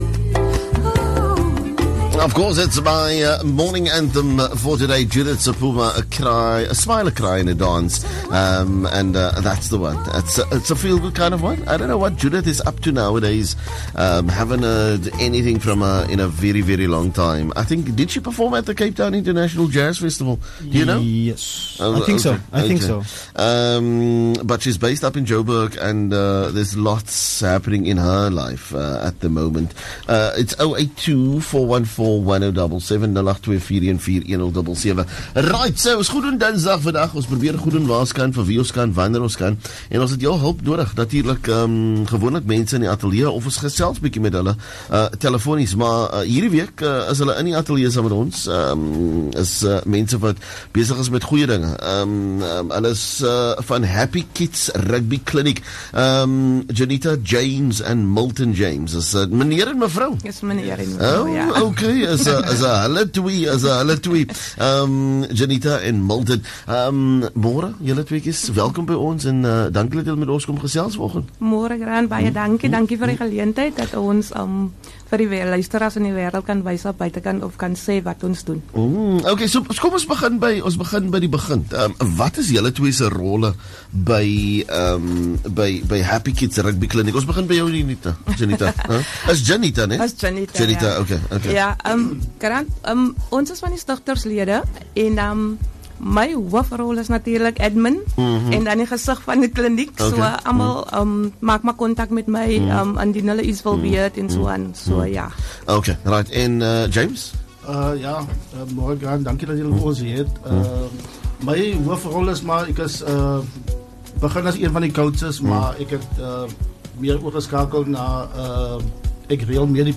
Happy Kids Rugby Clinic joined us in studio to give us more information about what their NPO does and their needs